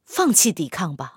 SU-122A开火语音1.OGG